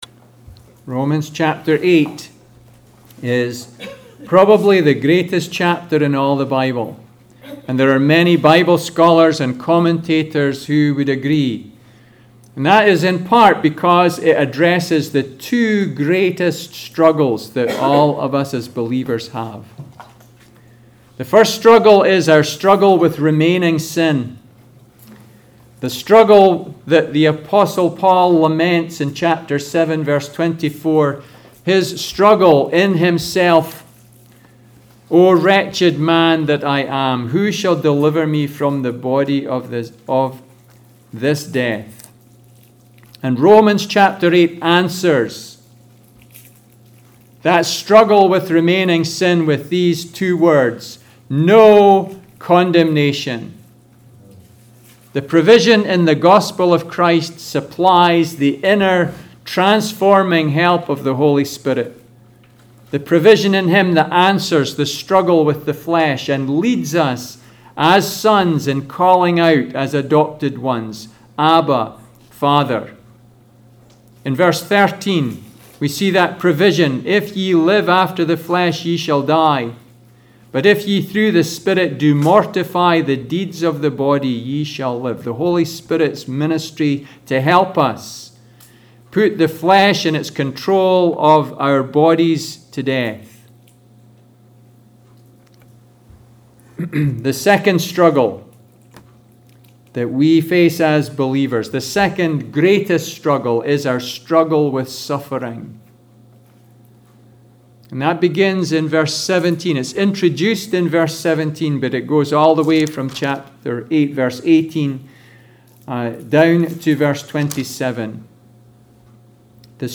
Passage: Romans 8:18-25 Service Type: Sunday Service